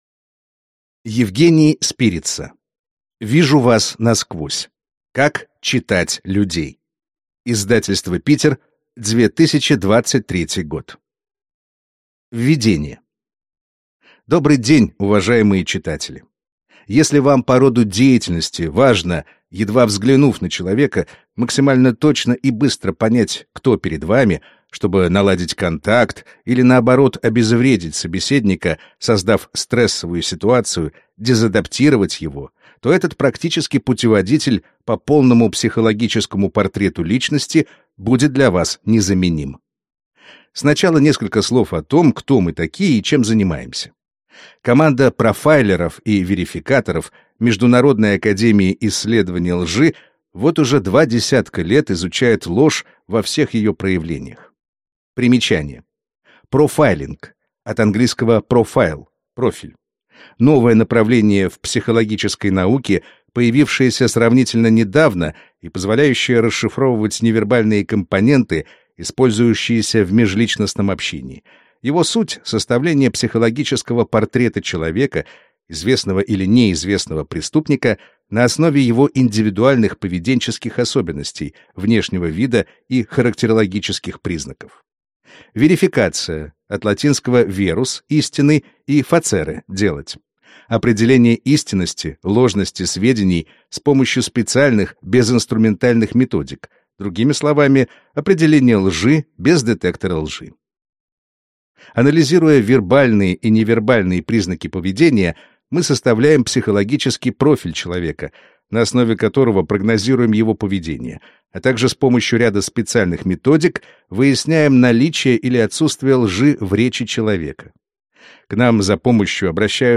Аудиокнига Вижу вас насквозь. Как «читать» людей | Библиотека аудиокниг